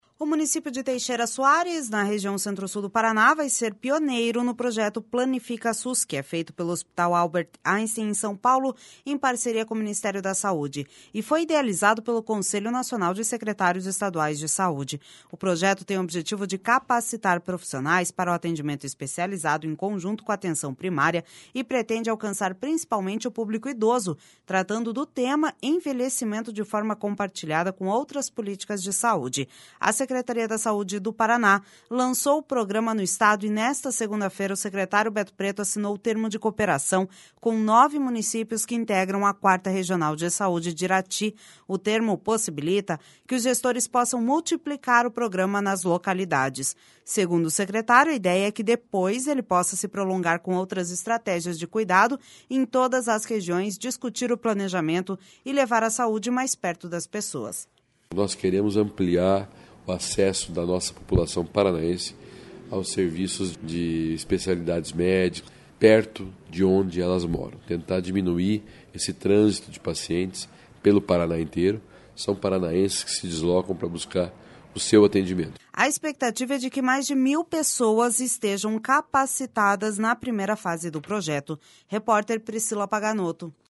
Segundo o secretário, a ideia é que depois ele possa se prolongar com outras estratégias de cuidado em todas as regiões, discutir o planejamento e levar a saúde mais perto das pessoas.// SONORA BETO PRETO//A expectativa é de que mais mil pessoas estejam capacitadas na primeira fase do projeto.